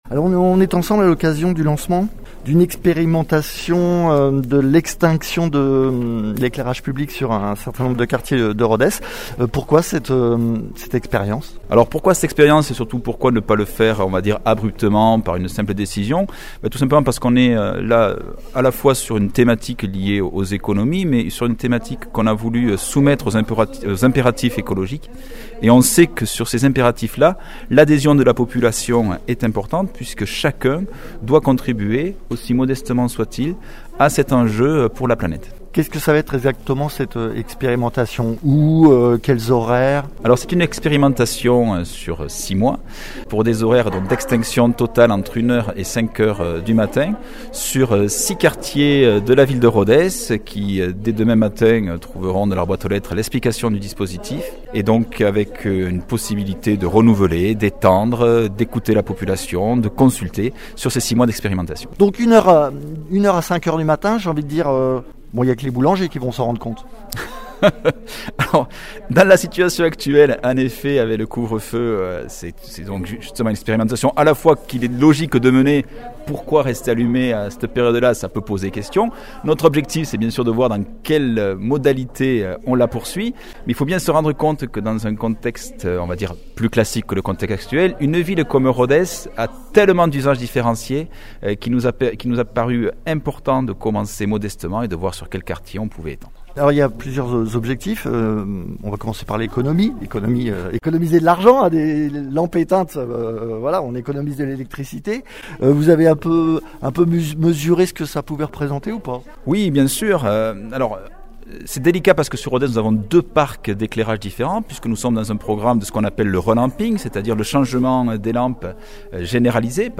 Interviews
Invité(s) : Christophe Lauras, deuxième adjoint à la mairie de Rodez en charge de l’environnement et des travaux